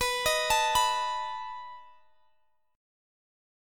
B7 Chord (page 3)
Listen to B7 strummed